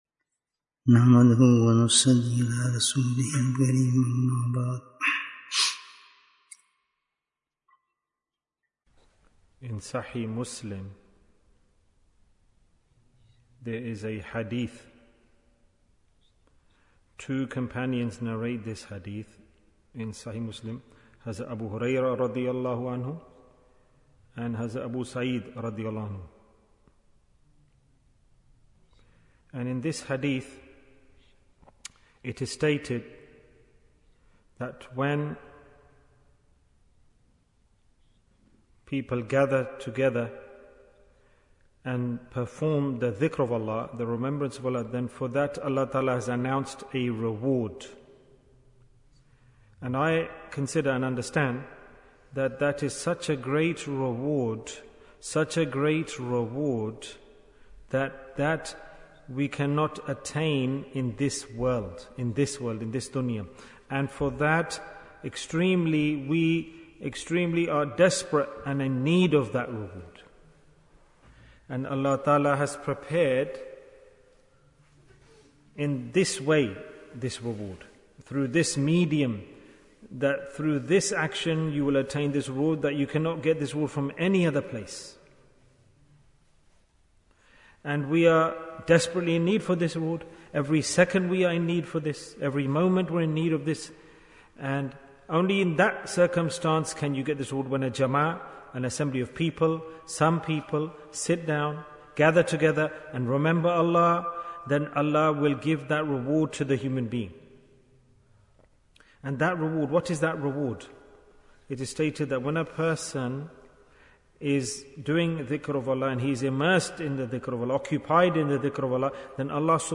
Peace Bayan, 17 minutes16th May, 2023